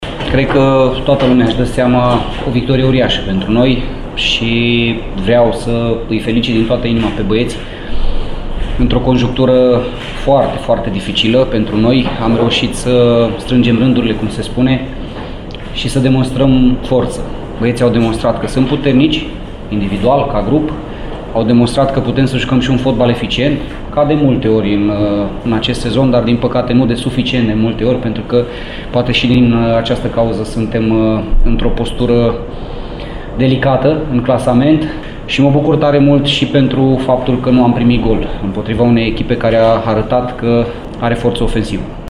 La sesiunea de declarații